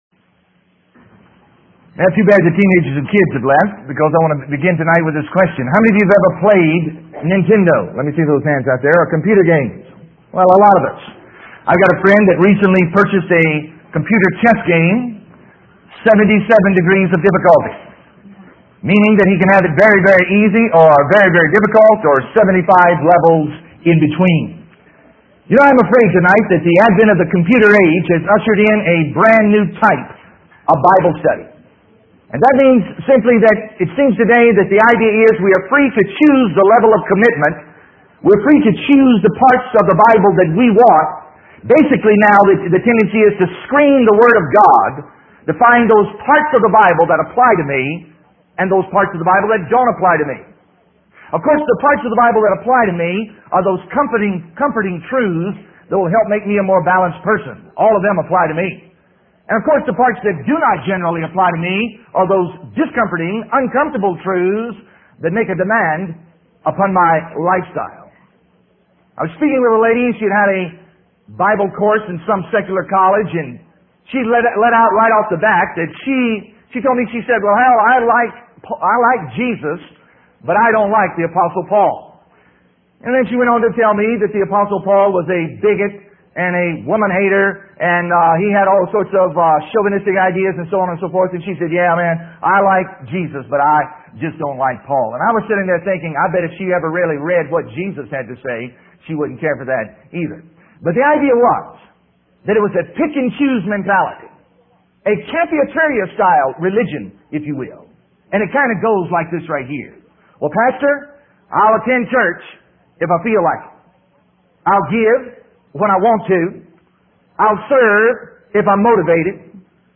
In this sermon, the speaker shares a personal story about his lack of obedience as a child. He emphasizes the importance of immediate obedience to God's commands. The speaker then highlights three key elements in the Bible: facts, commandments, and promises.